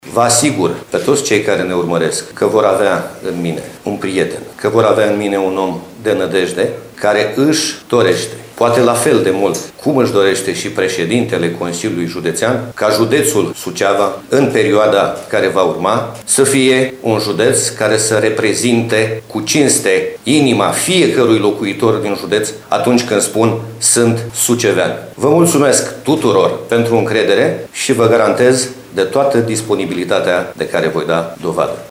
Noul prefect al județului Suceava TRAIAN ANDRONACHI a fost învestit astăzi în funcție, în prezența oficialităților județene și a colegilor din PSD.
După ce a depus jurământul de credință, ANDRONACHI a ținut un scurt discurs.